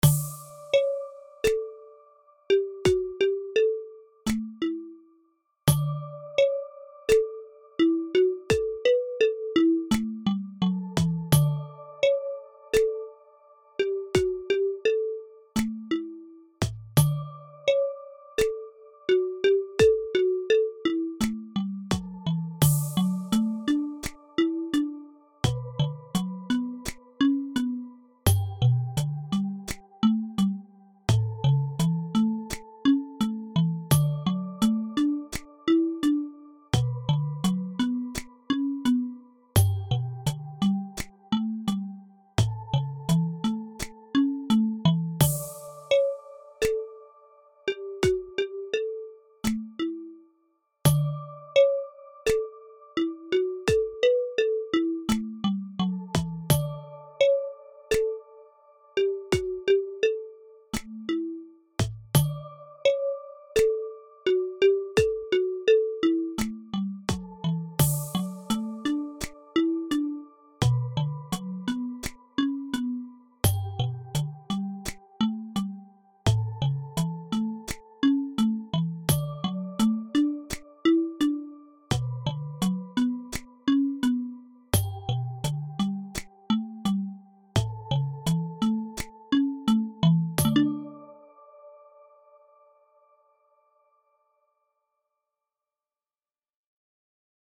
ゆったりしたBPMとシンプルな楽器構成。
のんびり感があります。